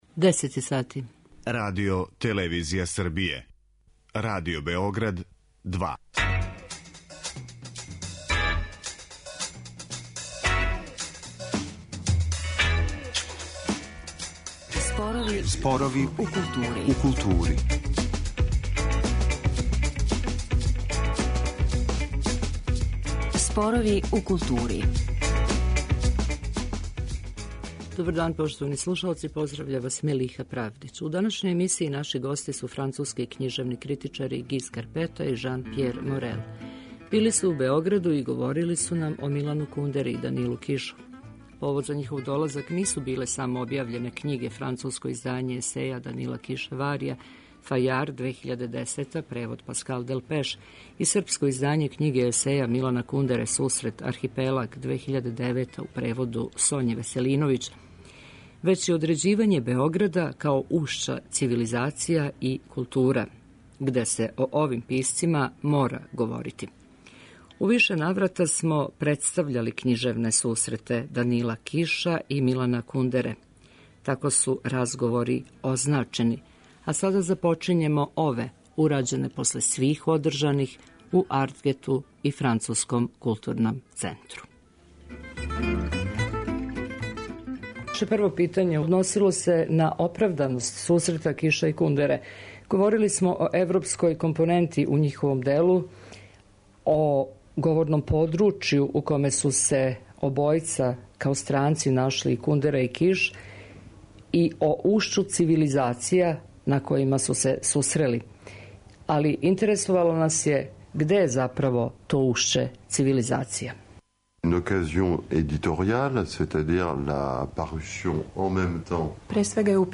Разговор ћете чути у емисији Спорови у култури.